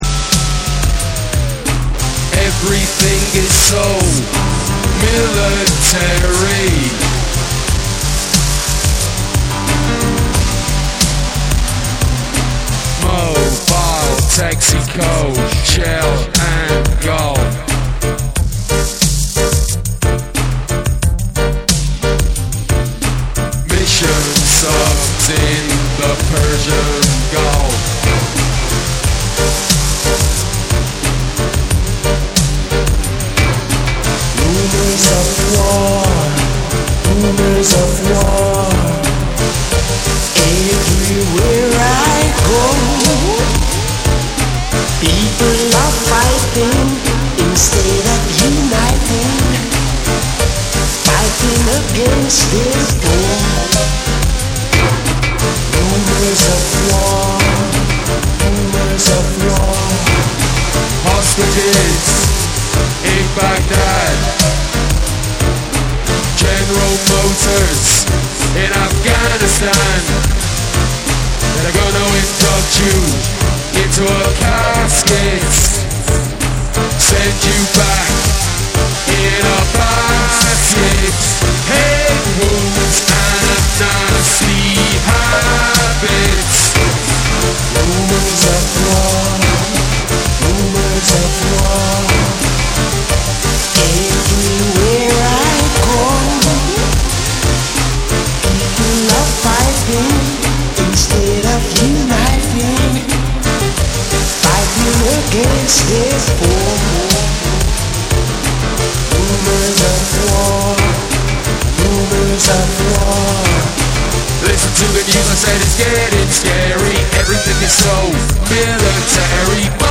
」は、ヘヴィなベースと攻撃的なビートがうねるフロア仕様のダンス・トラック。
ダビーでスローモーな展開が際立つエレクトリック・ダブを披露。
BREAKBEATS / REGGAE & DUB